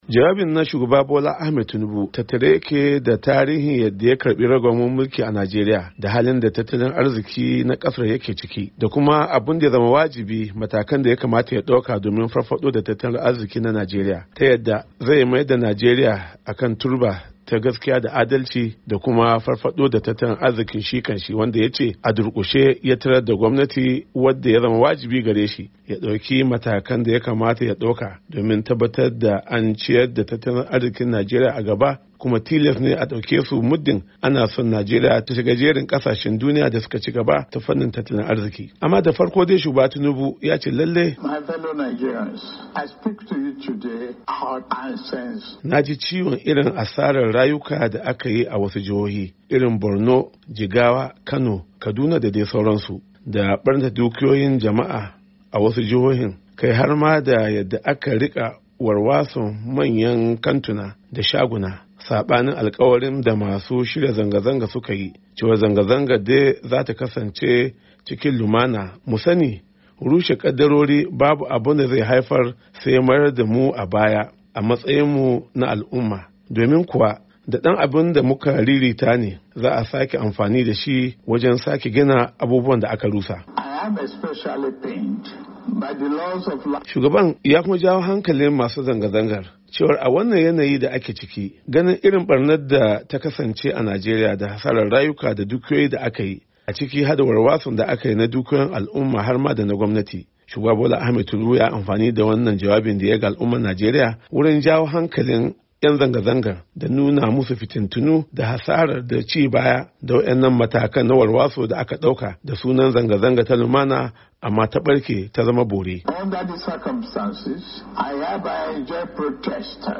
Jawabin Shugaban Kasa Bola Tinubu Akan Zanga-Zanga A Najeriya